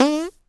cartoon_boing_jump_08.wav